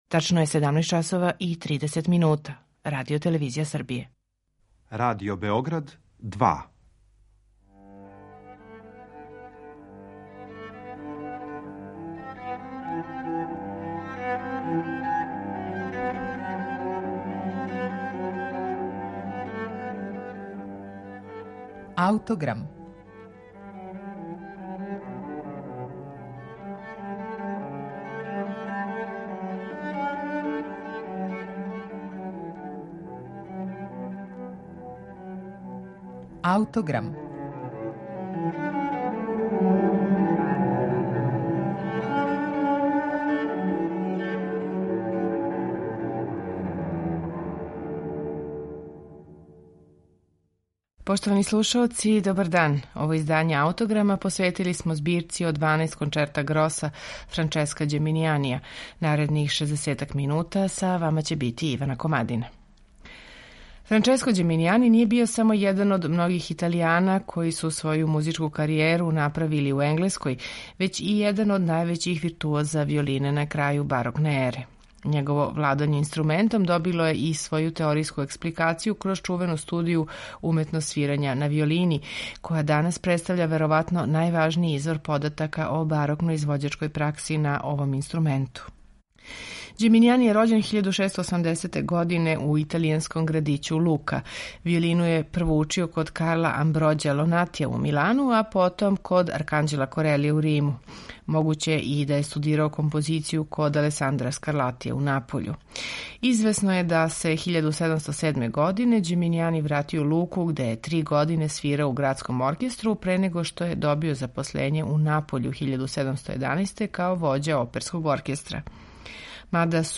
Овде, као и у свим наредним његовим концертима, налазимо кончертино групу квартета као супротстављену текстури трија, док се рипијено састоји од виолина у две деонице, виолончела и баса.
У данашњем Аутограму представићемо првих пет концерата из ове Ђеминијанијеве збирке. Слушаћете их у интерпретацији ансамбла „I musici".